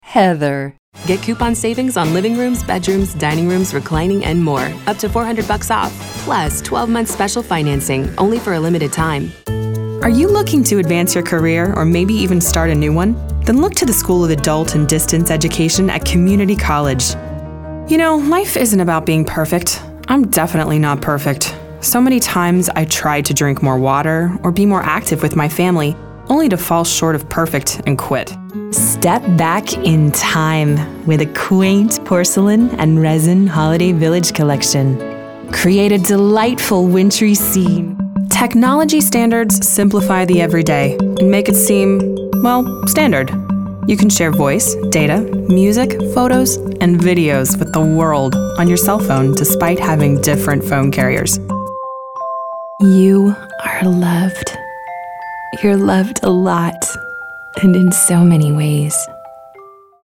Commercial Reel 2025
accented, accented English, anti-announcer, british, caring, concerned, confessional, conversational, genuine, real, serious, sincere, soft-spoken, storyteller
accented, accented English, announcer, british, compelling, cool, english-showcase, impression, raspy, sexy, smooth